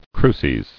[cru·ces]